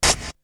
Beatbox 10.wav